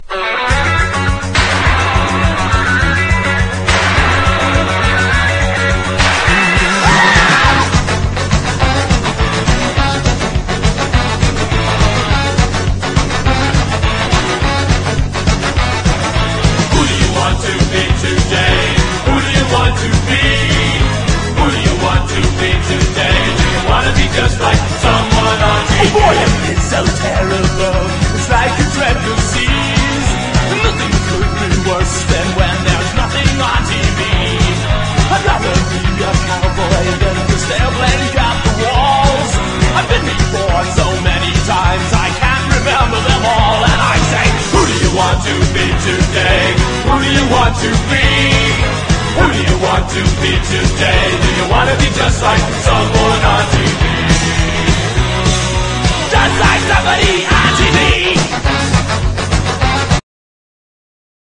パワーポップ/ウェイヴィー・ポップ好きにもオススメの80年美メロA.O.R.！